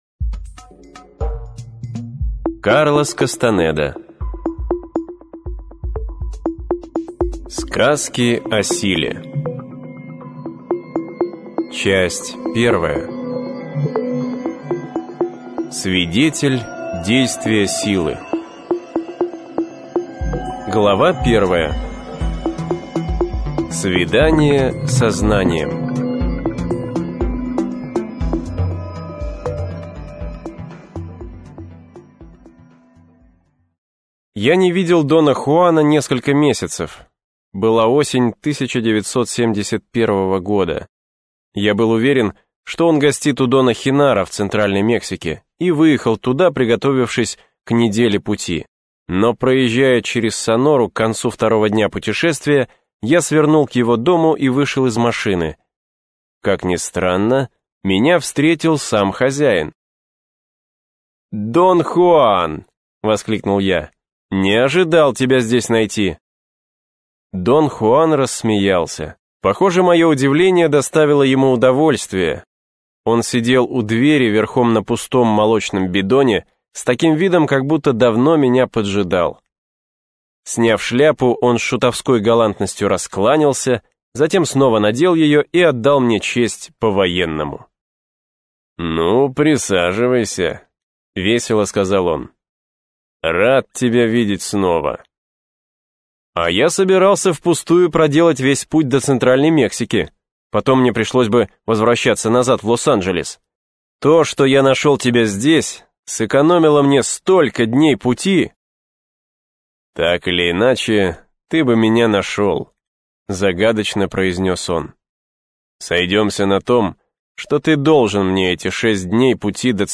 Аудиокнига Сказки о силе | Библиотека аудиокниг